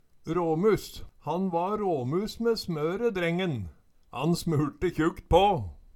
Høyr på uttala Ordklasse: Adjektiv Kategori: Karakteristikk Attende til søk